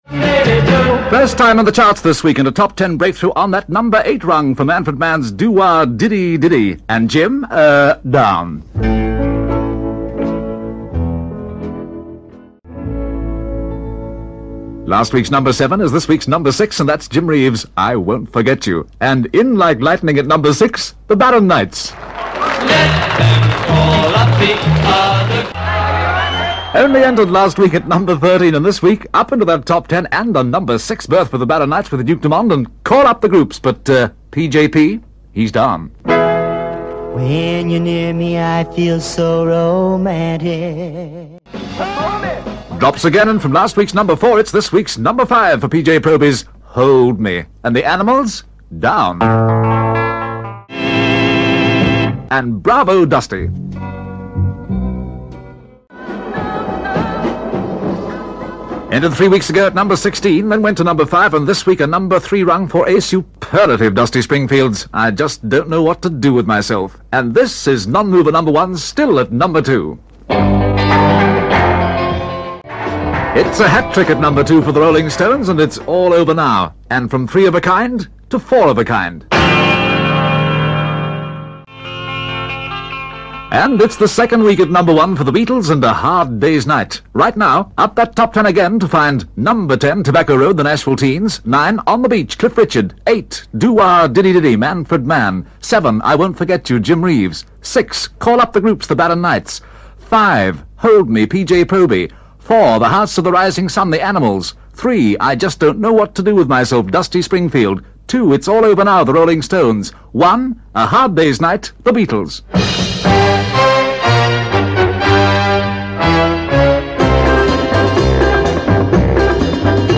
Known for hosting Pick of The Pops; and for the way he used his voice as a real instrument. He made an art form of the, erm, hesitation; and naturally mastered catch phrases: ‘Stay Bright’.
On those early BBC recordings, he sounds peculiarly English.